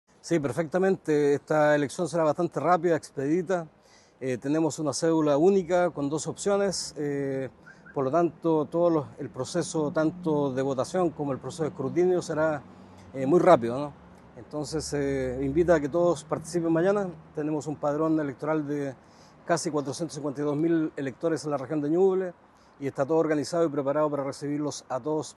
El anuncio se realizó en un punto de prensa efectuado la jornada del sábado, donde participaron el Jefe de la Defensa Nacional en Ñuble, general Patricio Valdivia, el director regional del Servel, Aldo Valenzuela, y la general jefe de zona de Carabineros, Loreto Osses.